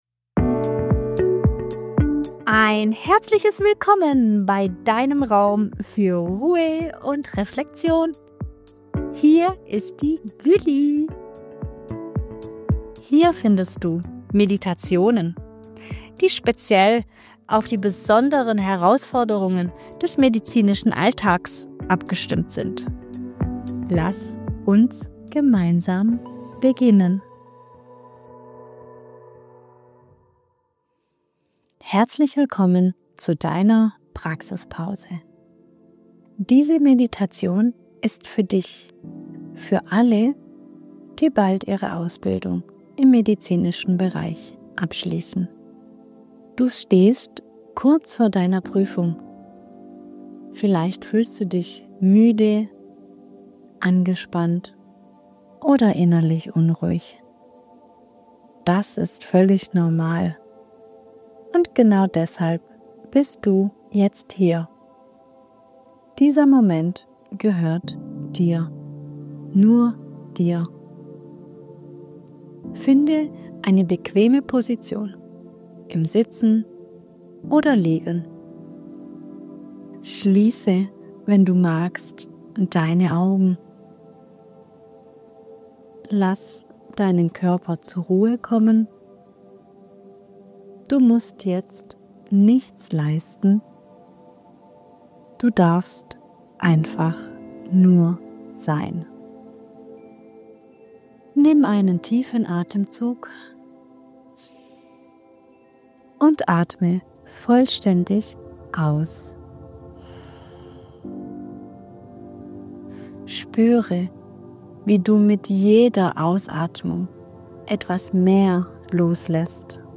Meditation zur Prüfungsvorbereitung | Stressfrei zu Deinem Abschluss im Gesundheitswesen